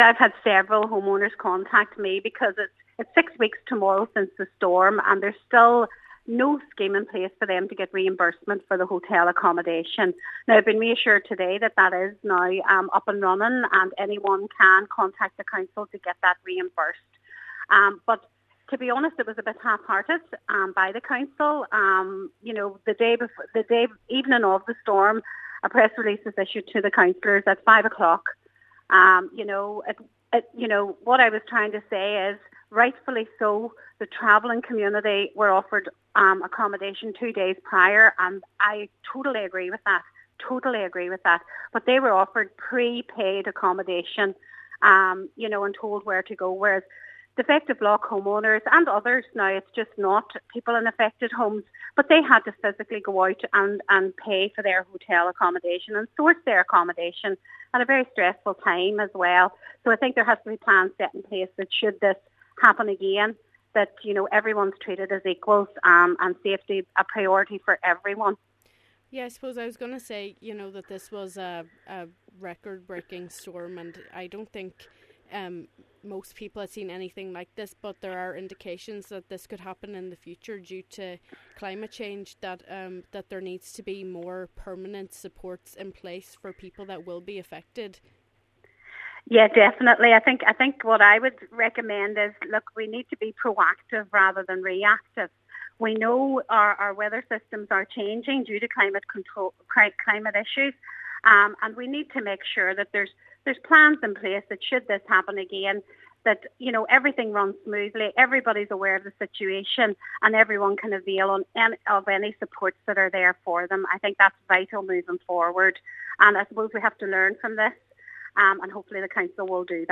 At a recent meeting of the Donegal County Council Defective Concrete Block Committee, Councillor Joy Beard was assured that those who used hotels can now claim their expenses: